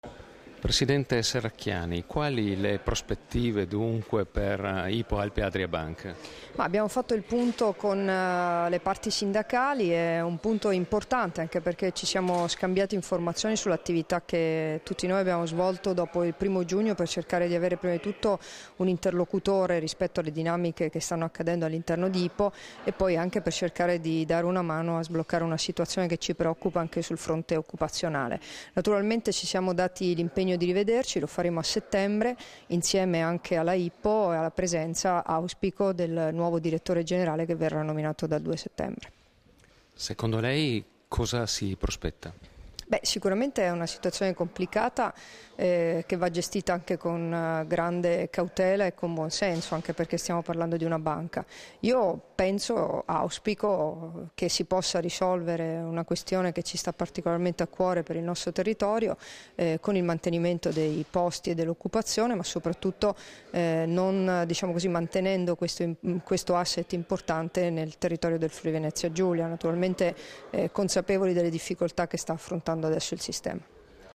Dichiarazioni di Debora Serracchiani (Formato MP3) rilasciate a margine dell’incontro con i rappresentanti sindacali di Hypo Alpe Adria Bank, a Udine il 12 agosto 2013 [1192KB]